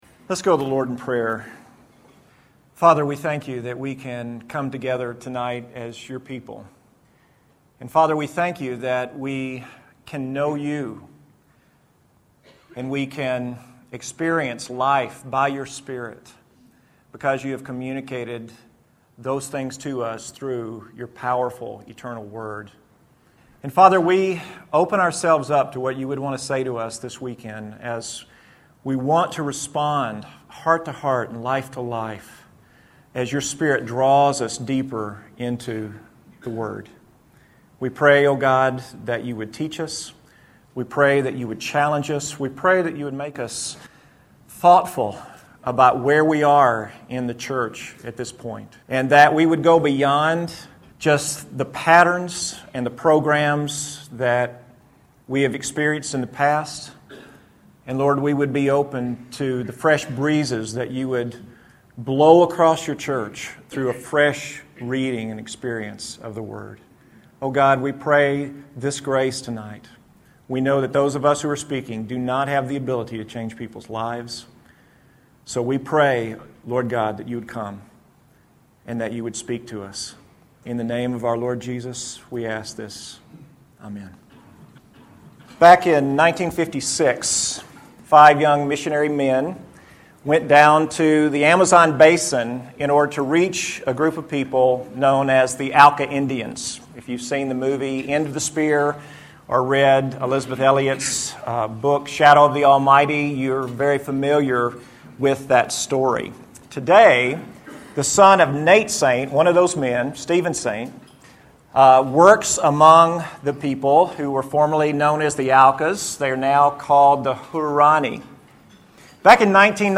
Address: Theme Interpretation